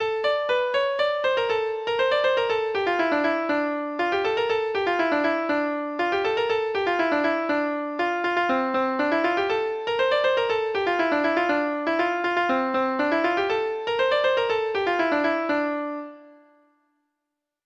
Folk Songs from 'Digital Tradition' Letter S Staines Morris
Free Sheet music for Treble Clef Instrument